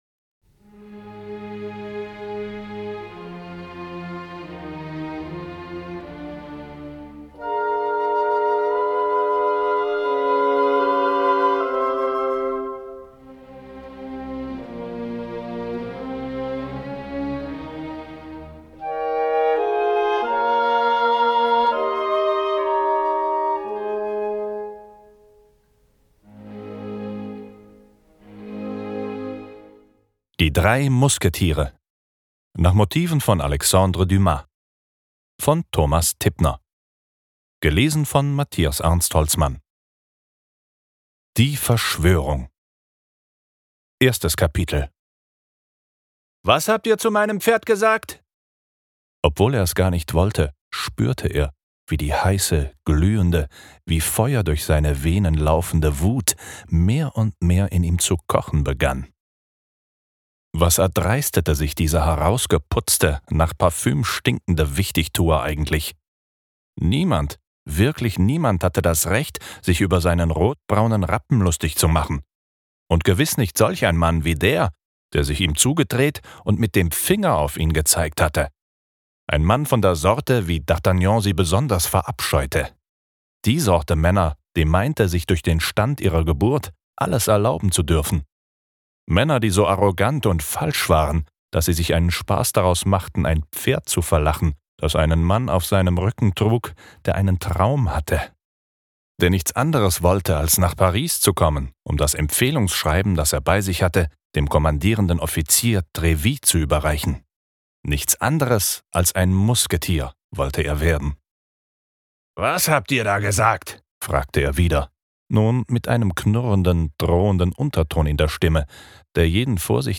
Die drei Musketiere - Alexandre Dumas - E-Book + Hörbuch